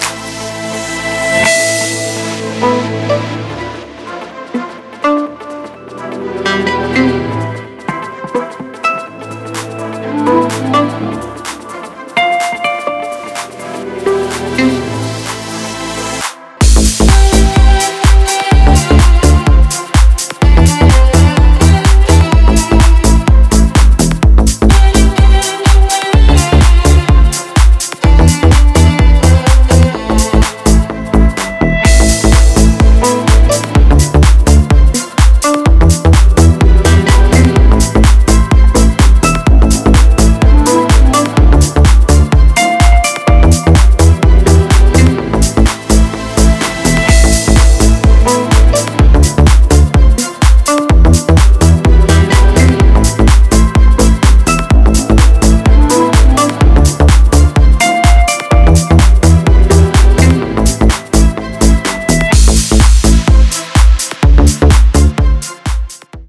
• Качество: 320, Stereo
красивые
без слов
инструментал